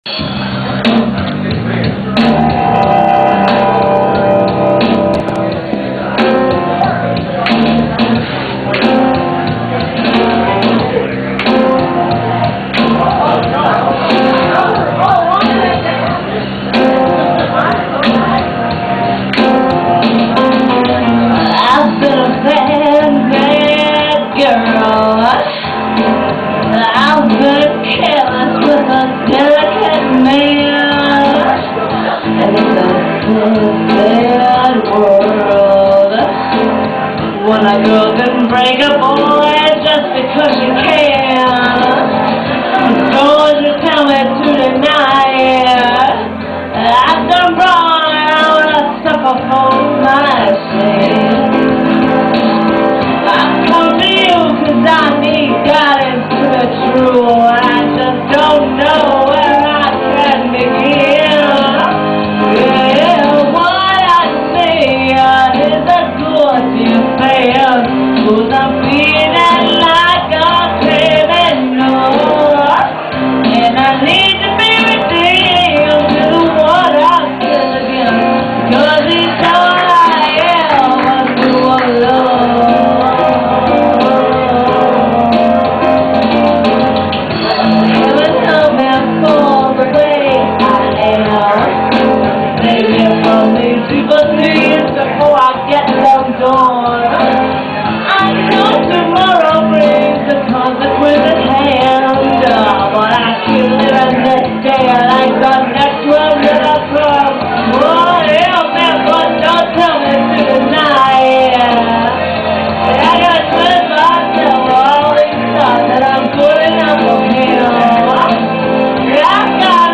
October 10, 2003 live recordings
This was recorded live October 10 2003.